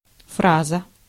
Ääntäminen
IPA: /ˈfrazə/